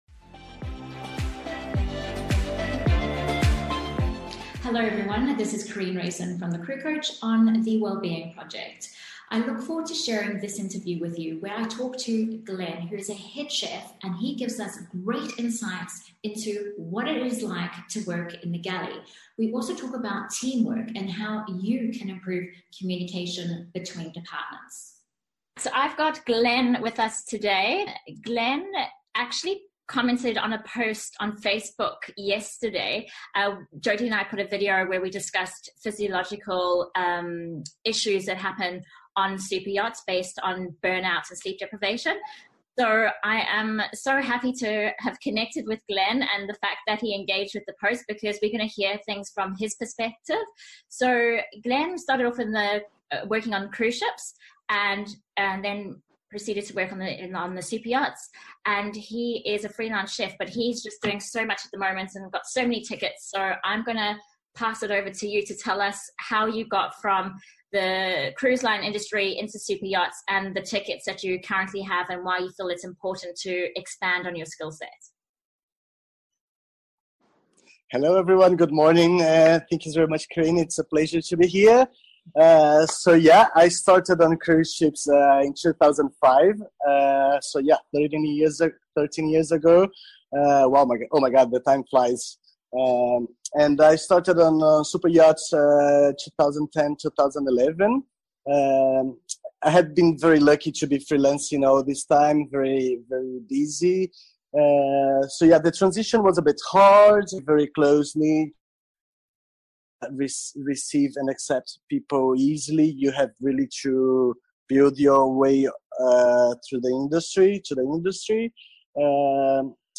A frank discussion about teamwork and how it affects the smooth or not so smooth running of the day to day operations on a Superyacht.